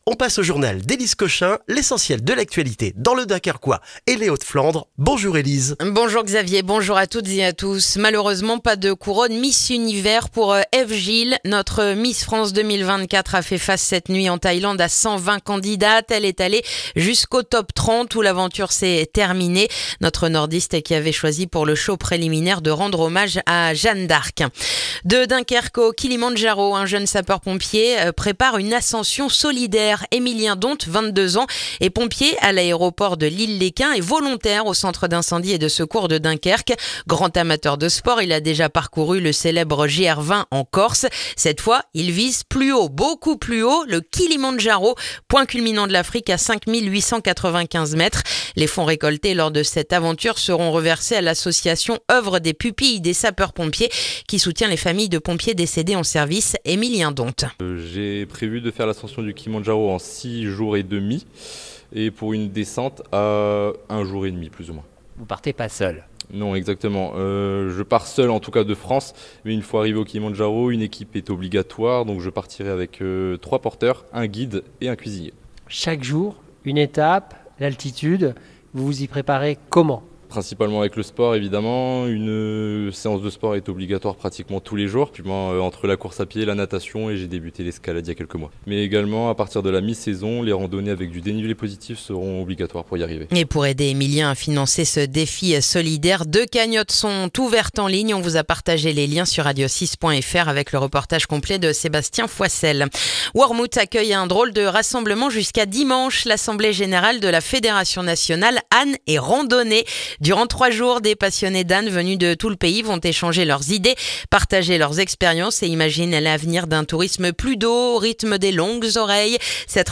Le journal du vendredi 21 novembre dans le dunkerquois